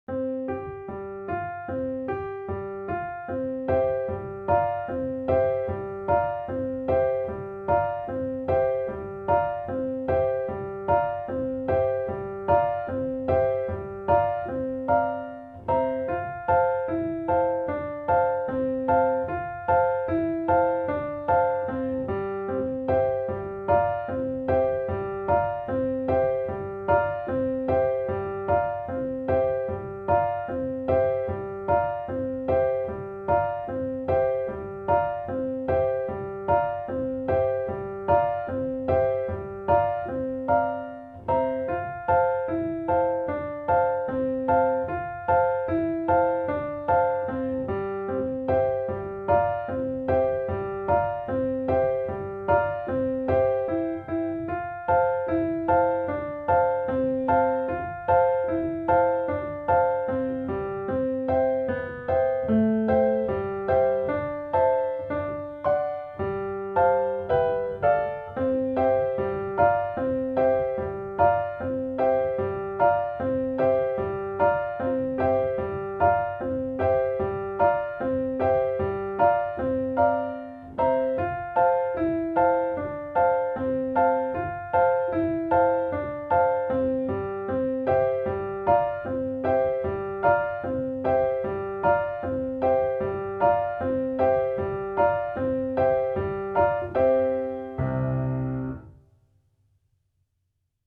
Piano Audio